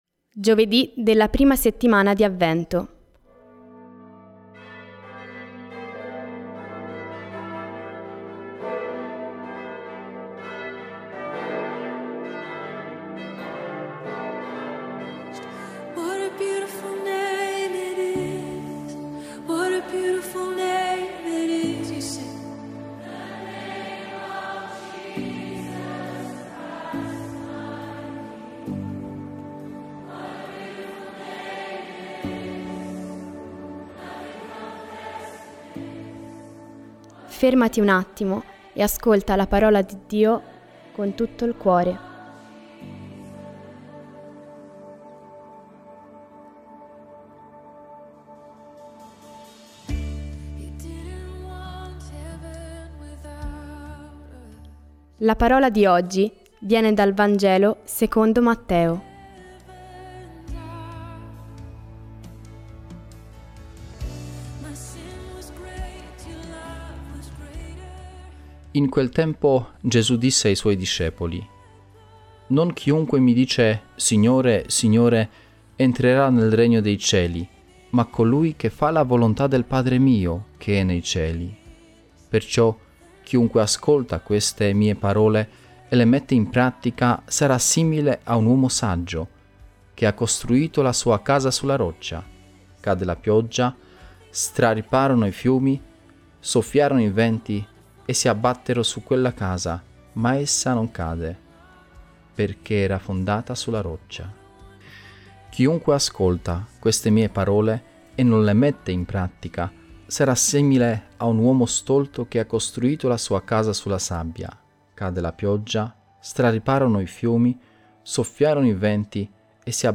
Musica degli Hillsong Worship: What A Beautiful Name